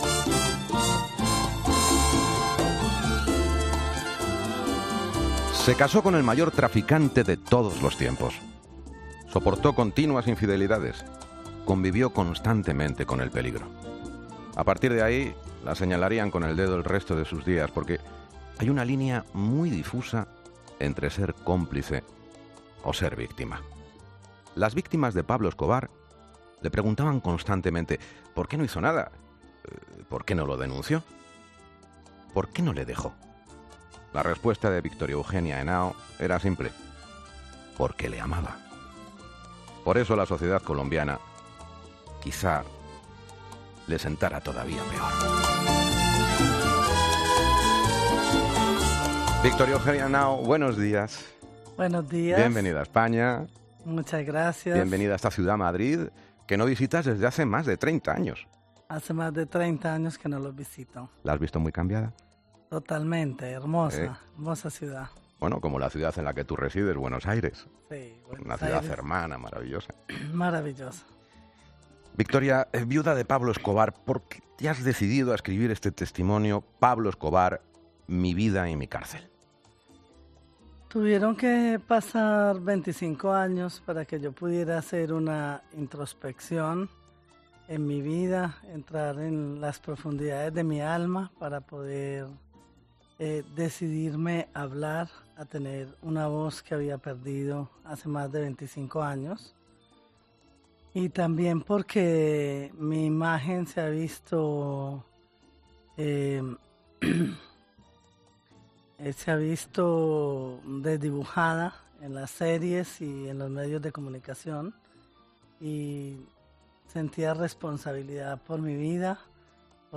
Victoria Eugenia Henao, en Herrera en COPE